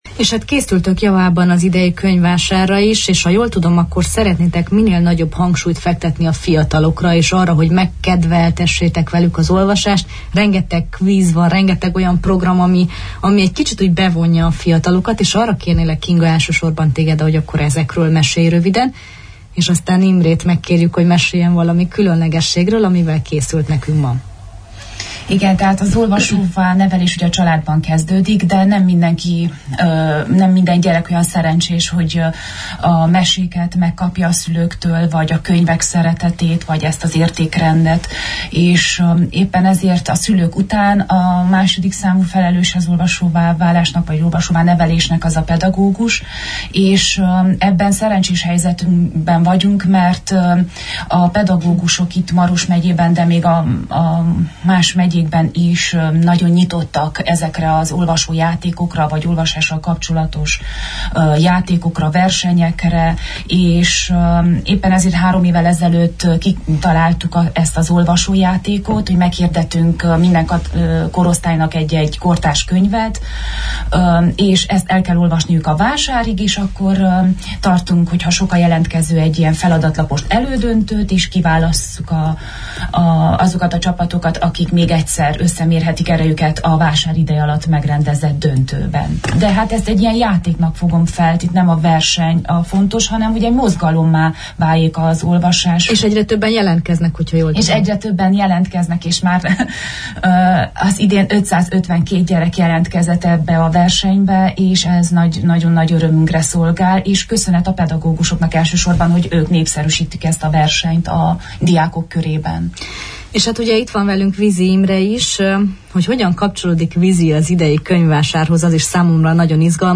A hangulatos beszélgetés egy részlete máris visszahallgatható.